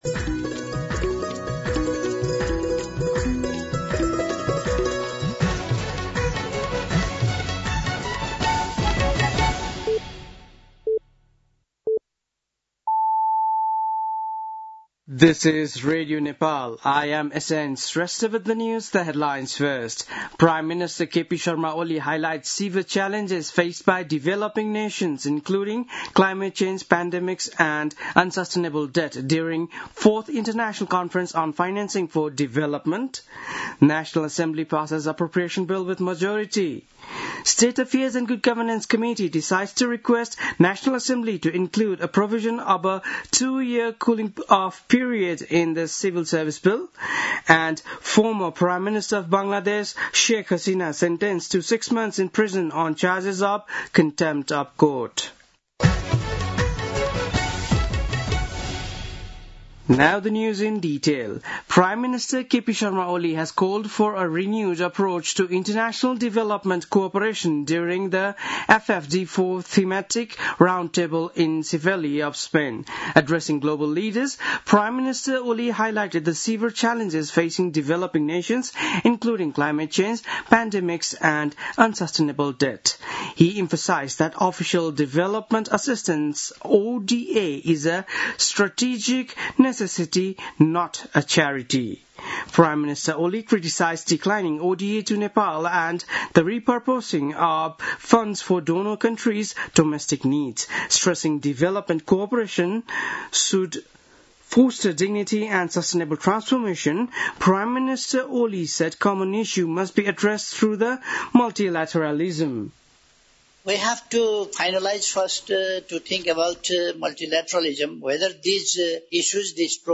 बिहान ८ बजेको अङ्ग्रेजी समाचार : १८ असार , २०८२